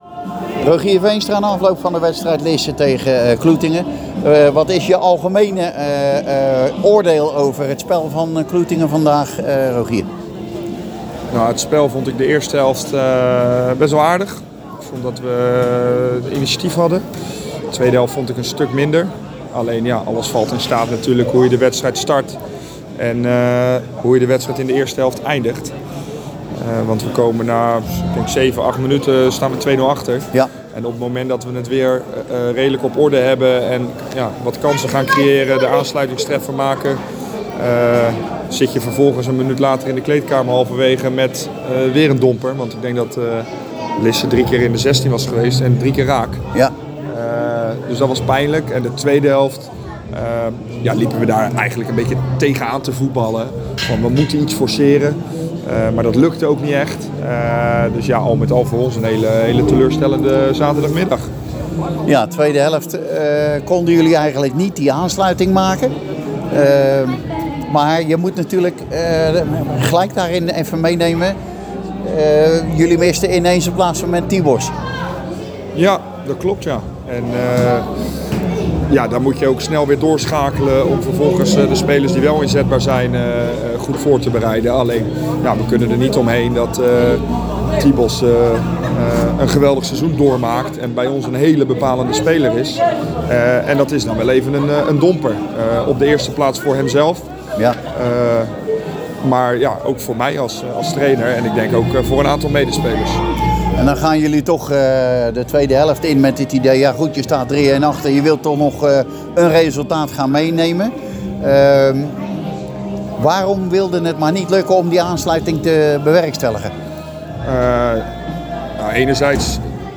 Tekst en interview